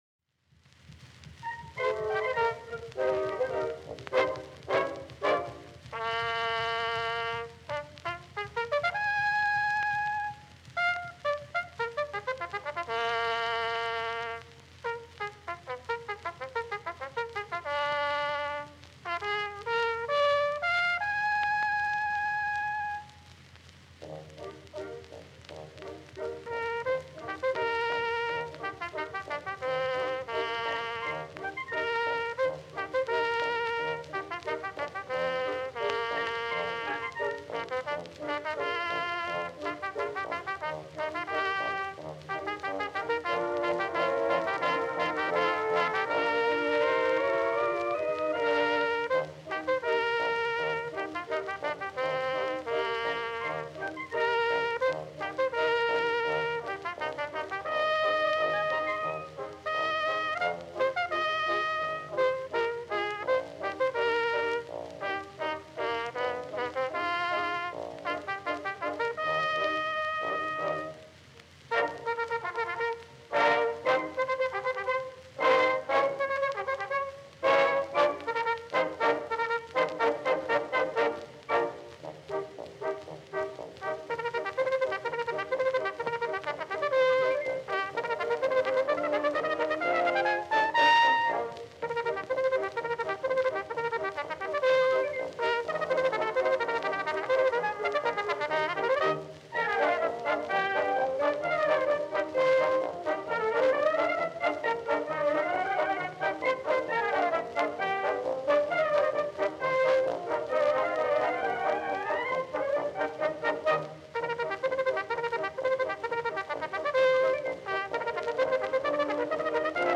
cornet